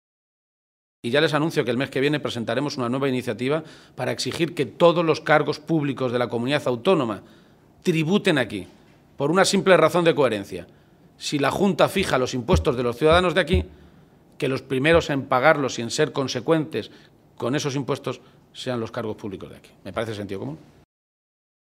El líder de los socialistas castellano-manchegos ha realizado estas declaraciones en un desayuno informativo en Ciudad Real, donde ha estado acompañado por el secretario provincial del PSOE en esta provincia, José Manuel Caballero, por la secretaria de Organización, Blanca Fernández, y por la portavoz regional, Cristina Maestre.